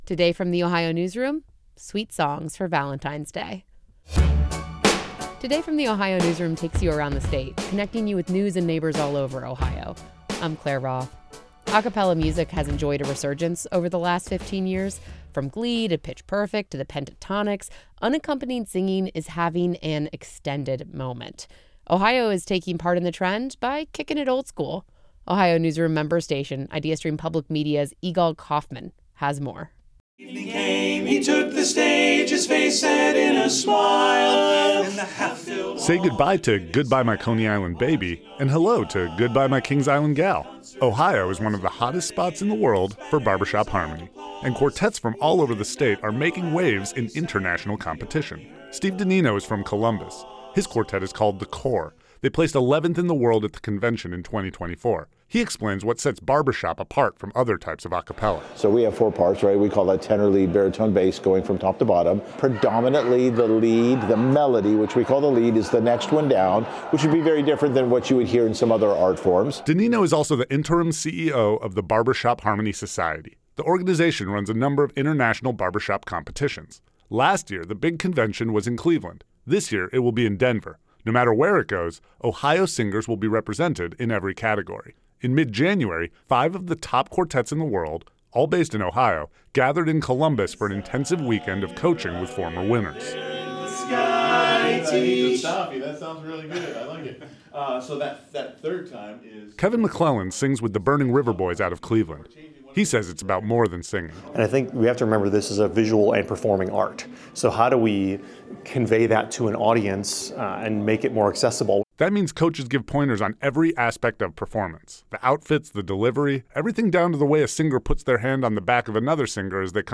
Sweet harmony rings as Ohio singers hone their barbershop performances
At the Top Gun School, these quartets received direct coaching in sessions with past champions.